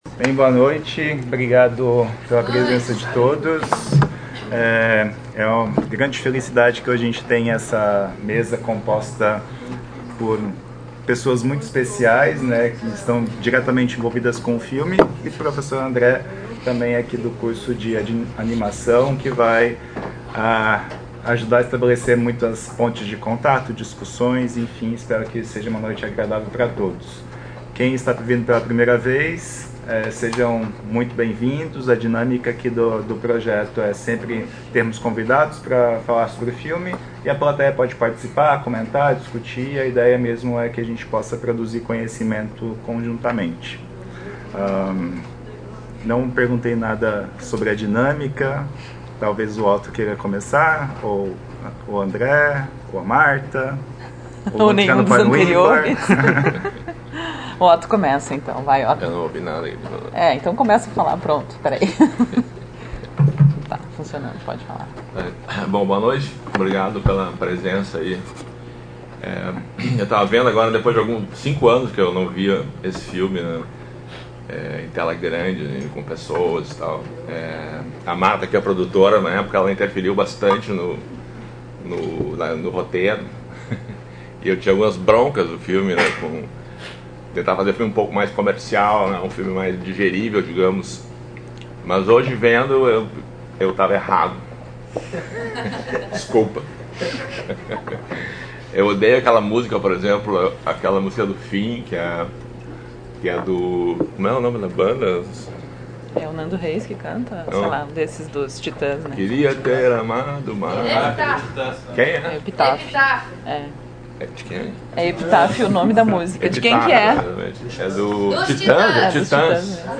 Comentários dos debatedore(a)s convidado(a)s
na sessão de exibição e debate do filme
no Auditório Elke Hering da Biblioteca Central da UFSC